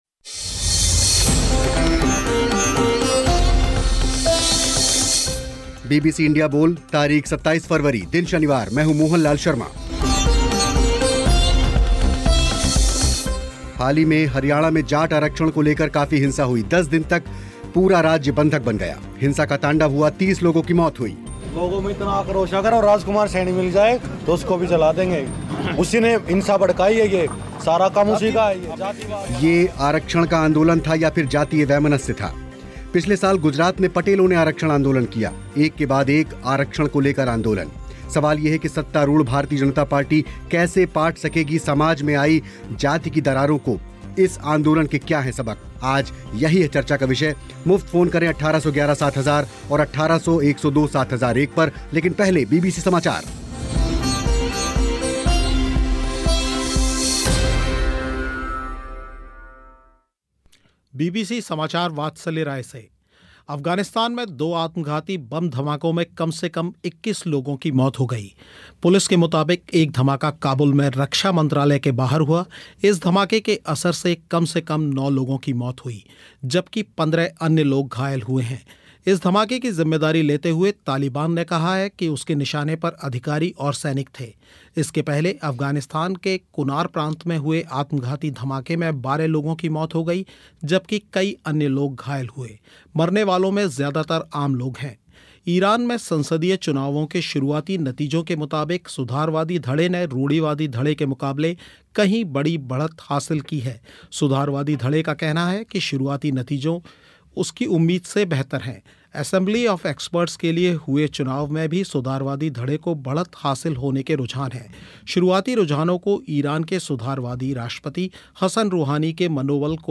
बीबीसी इंडिया बोल इसी पर हुई बहस